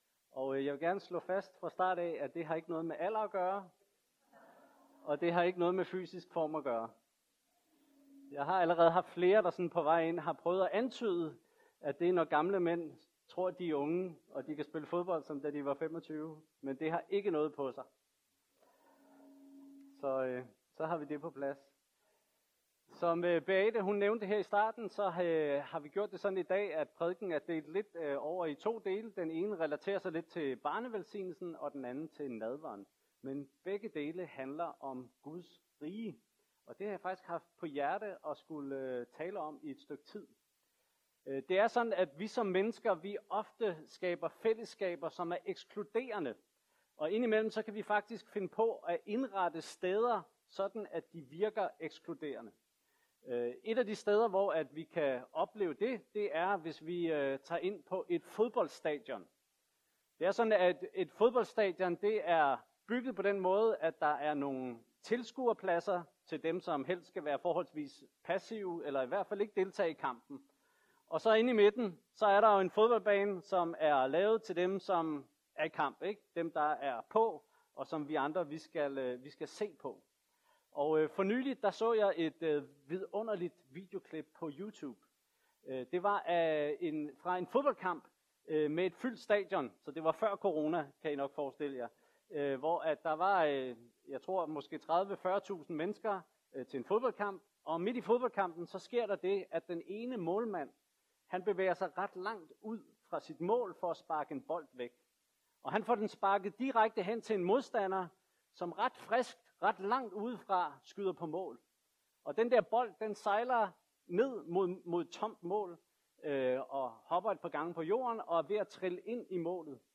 Dagens prædiken var originalt delt i to. Anden del begynder med en vittighed.
Gudstjeneste d. 11. Oktober 2020